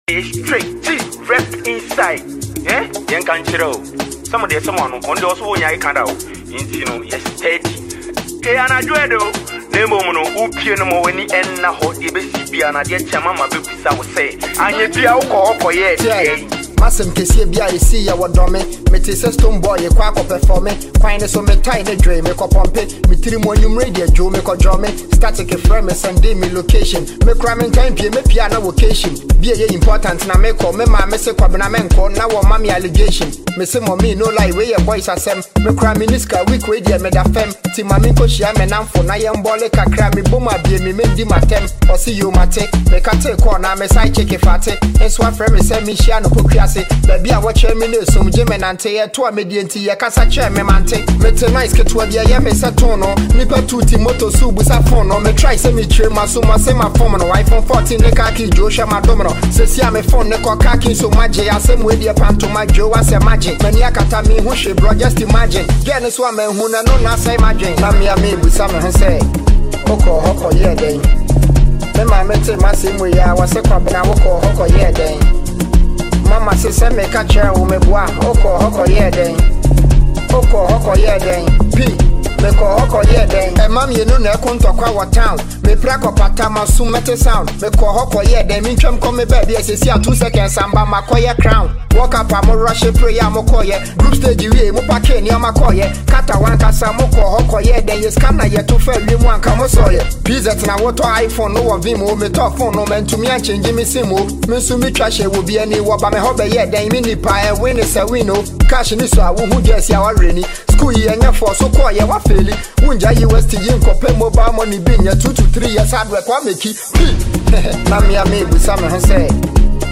Ghanaian hip hop recording artist